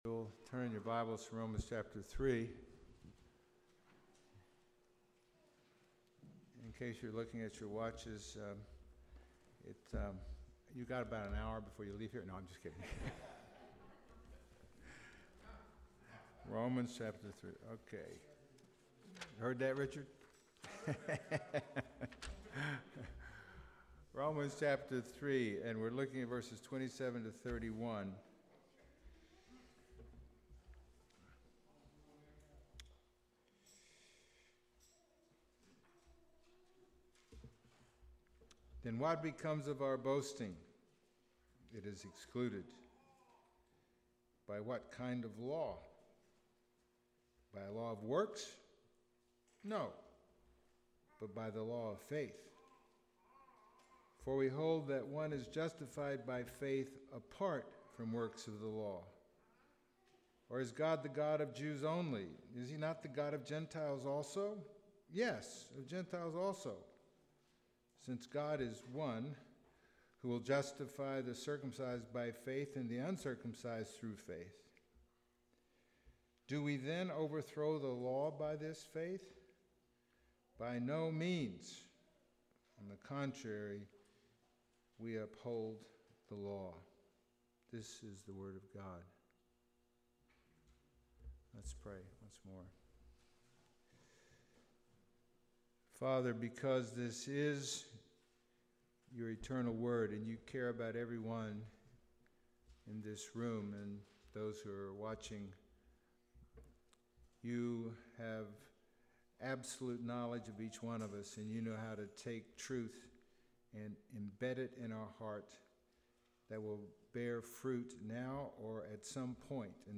Romans Passage: Romans 3:27-31 Service Type: Sunday Morning « What Are Genders For?